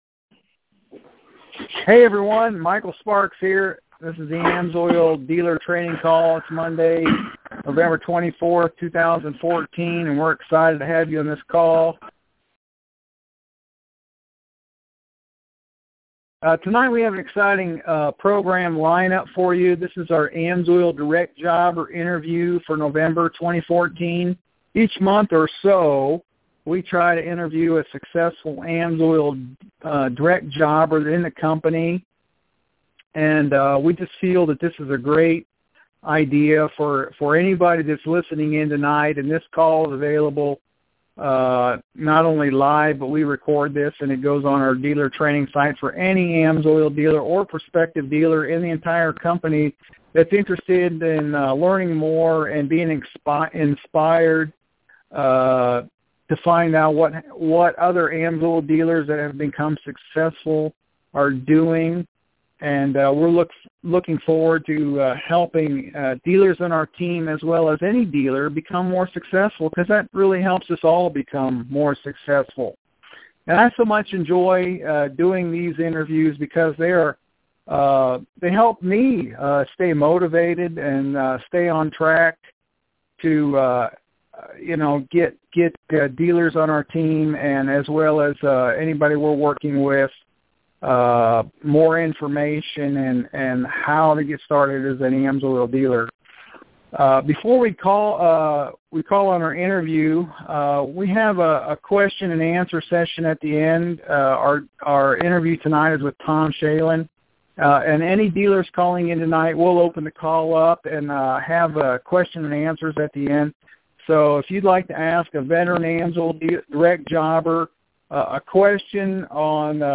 The AMSOIL Dealer training call monthly Direct Jobber Interview.